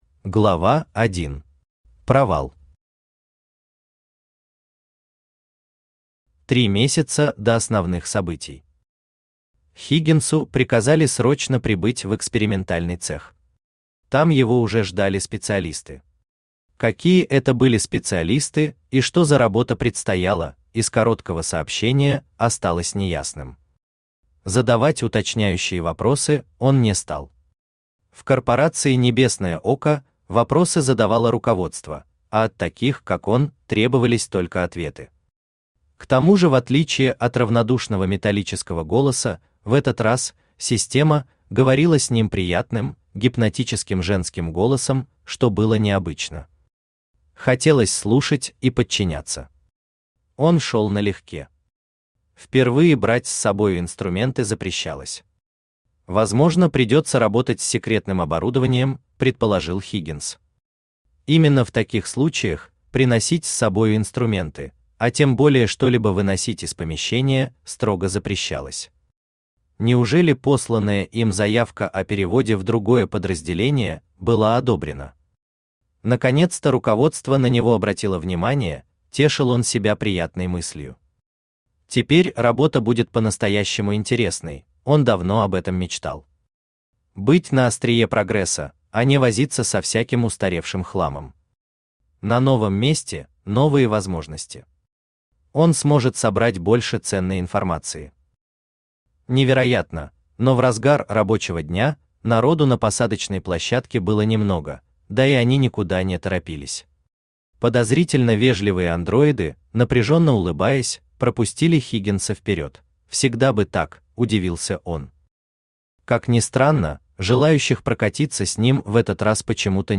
Аудиокнига МЕРЦАЮЩИЕ НЕБЕСА | Библиотека аудиокниг
Aудиокнига МЕРЦАЮЩИЕ НЕБЕСА Автор ТАМ АЛАН Читает аудиокнигу Авточтец ЛитРес.